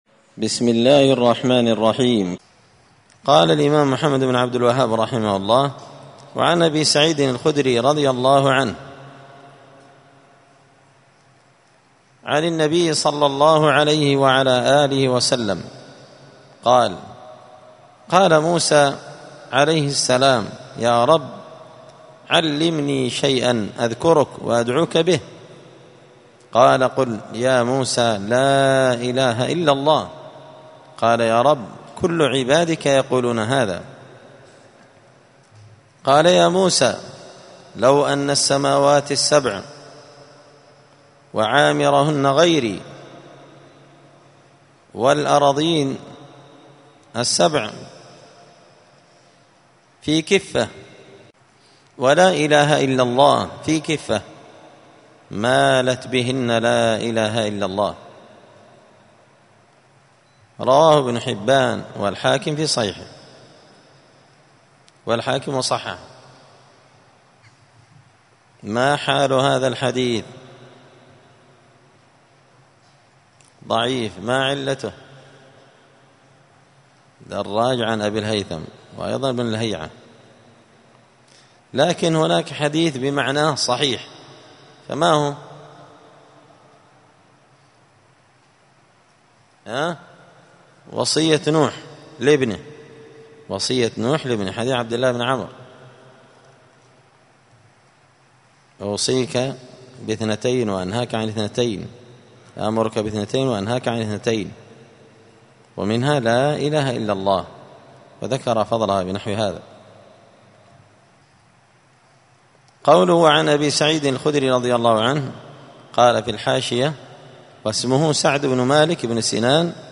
حاشية كتاب التوحيد لابن قاسم الحنبلي الدرس العاشر (10) {تابع للباب الثاني باب فضل التوحيد ومايكفر من الذنوب…}
دار الحديث السلفية بمسجد الفرقان بقشن المهرة اليمن